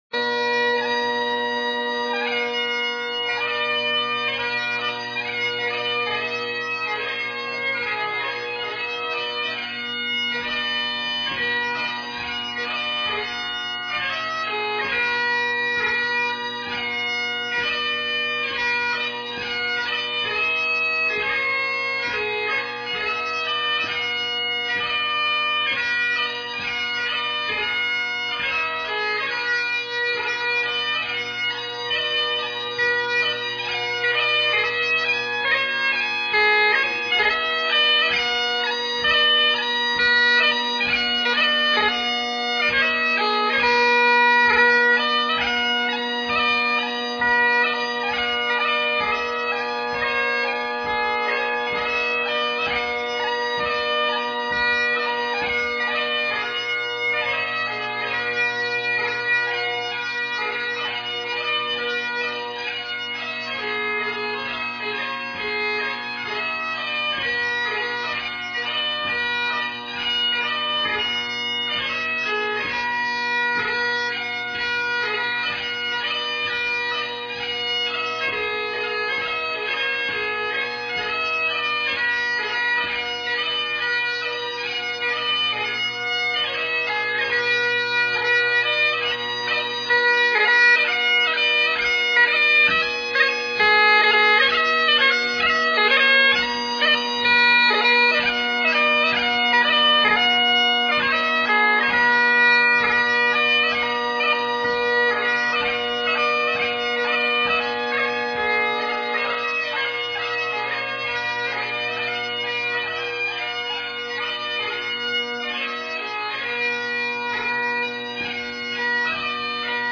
at St. Andrew's College - 2/4 Marches